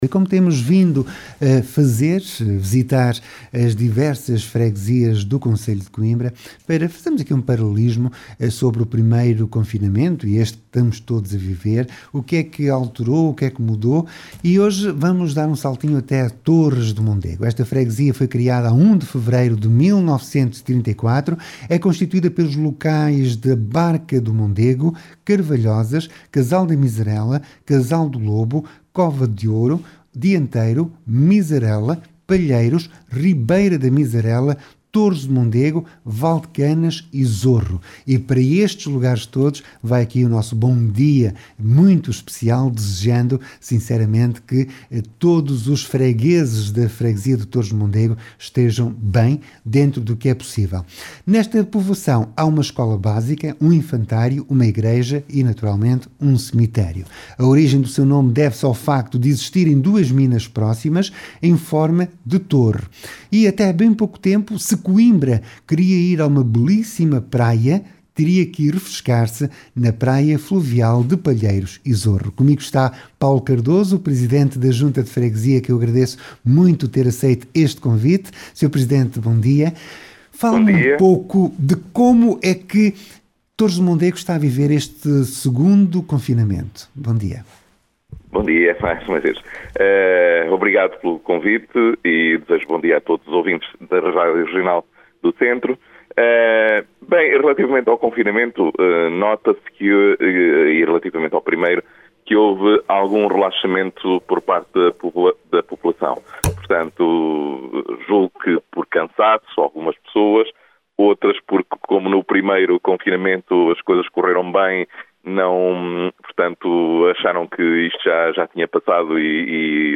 Torres do Mondego é a freguesia que hoje visitámos conversando com Paulo Cardoso, presidente da Junta, que fez o ponto de situação vivido neste segundo confinamento.